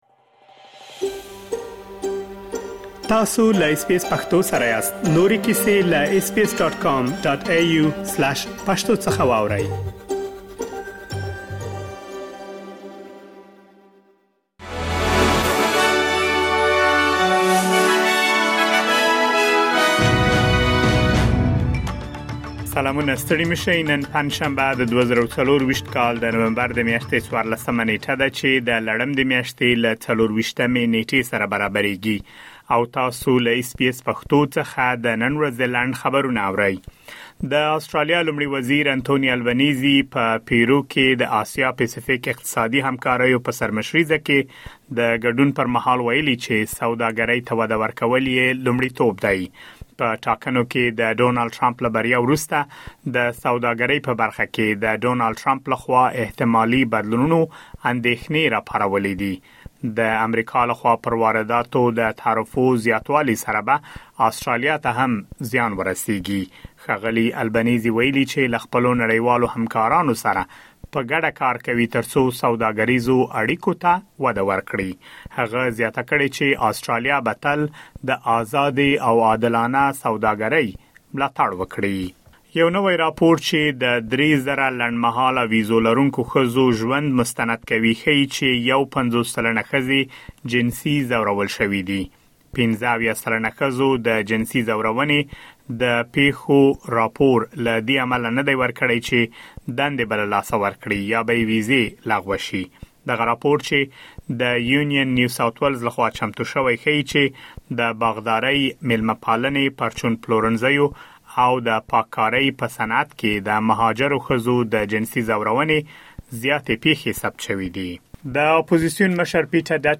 د اس بي اس پښتو د نن ورځې لنډ خبرونه |۱۴ نومبر ۲۰۲۴